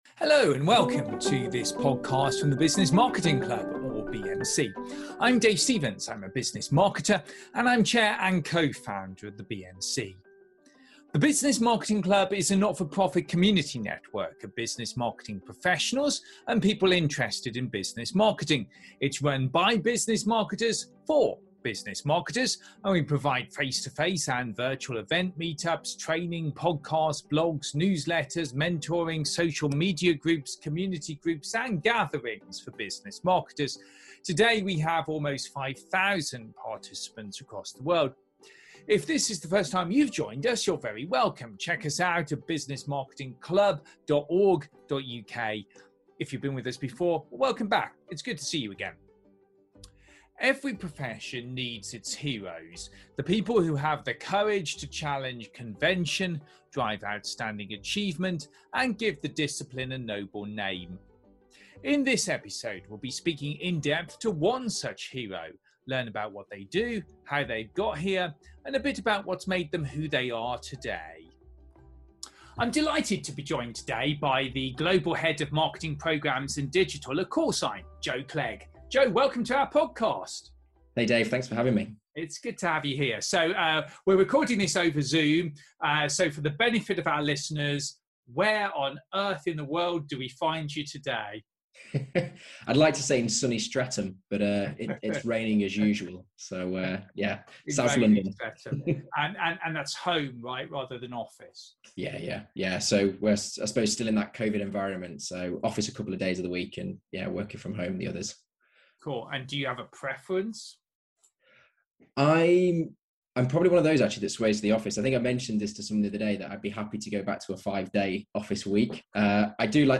Continuing a series of in-depth interviews with some of Business Marketing’s heroes.